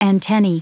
Prononciation du mot antennae en anglais (fichier audio)
Prononciation du mot : antennae